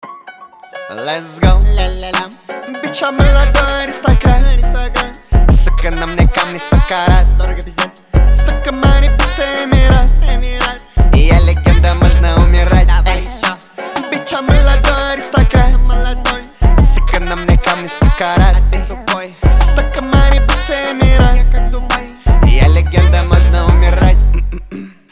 • Качество: 320, Stereo
пианино
качающие